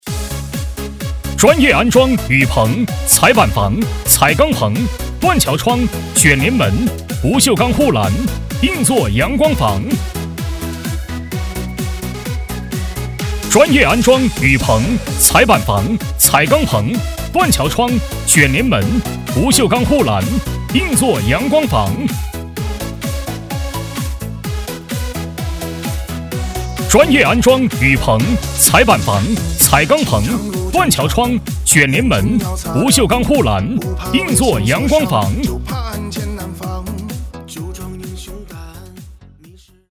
【男8号促销】专业安装雨棚的.mp3